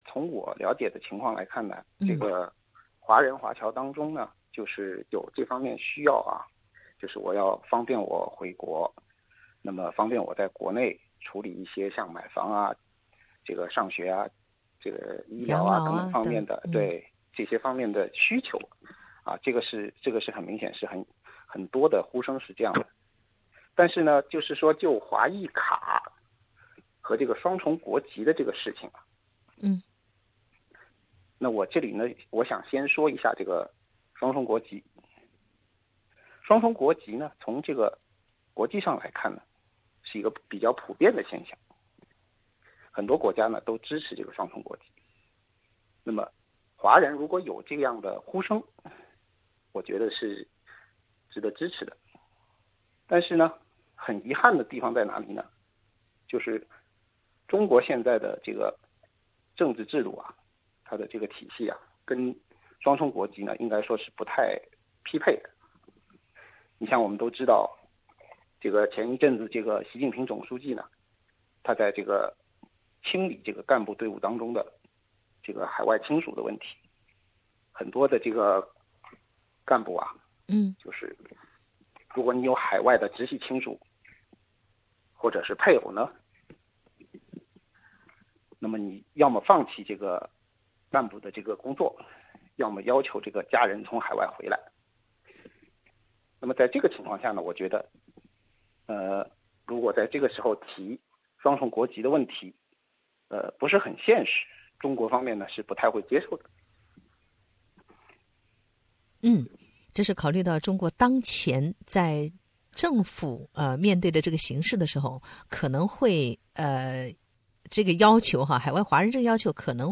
SBS 普通话电台